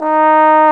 Index of /90_sSampleCDs/Roland L-CDX-03 Disk 2/BRS_Trombone/BRS_Tenor Bone 3